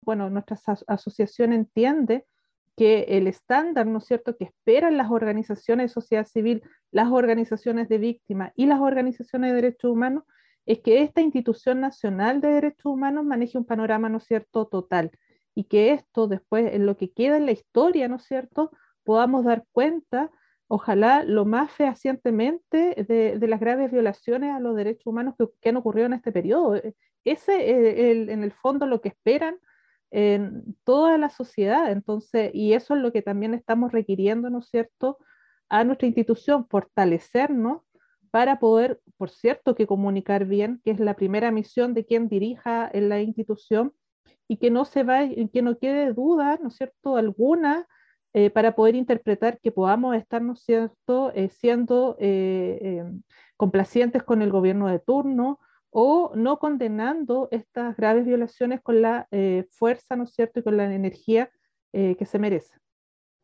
Escucha aquí la entrevista con la directiva de la Asociación de Funcionarios y Funcionarias del INDH.